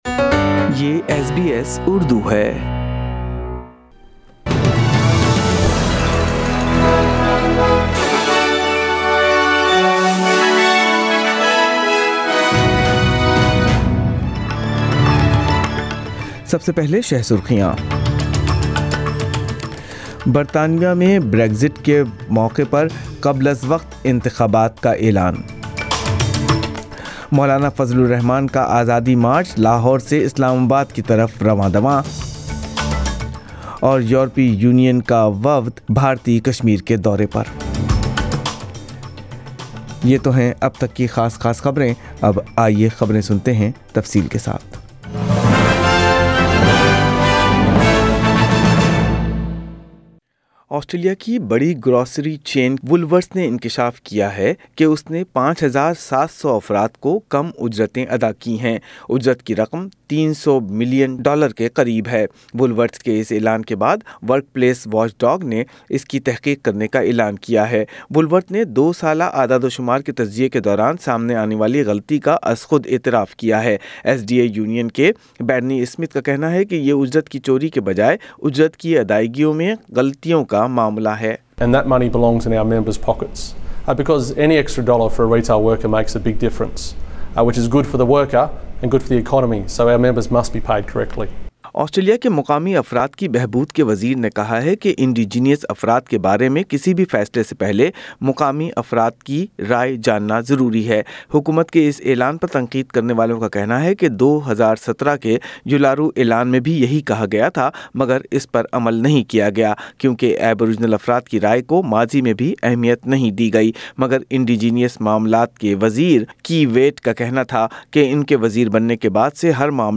اردو خبریں ۳۱ اکتوبر ۲۰۱۹